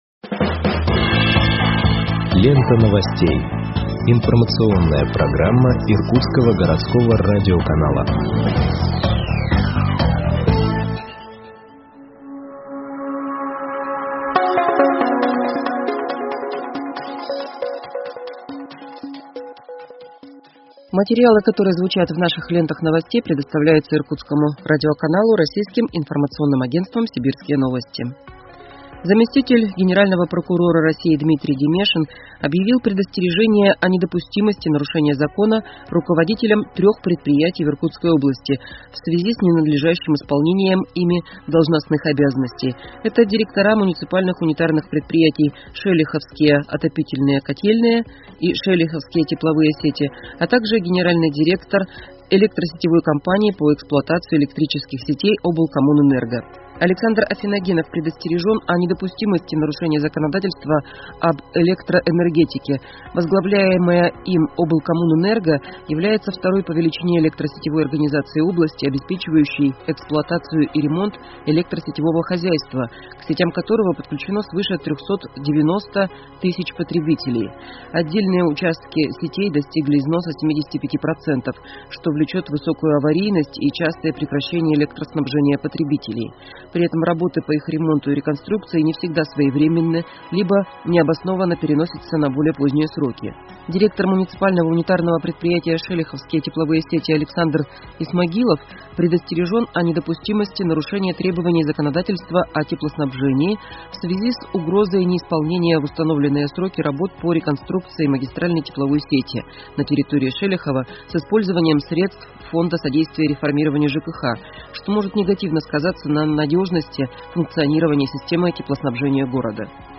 Выпуск новостей в подкастах газеты Иркутск от 28.09.2021 № 1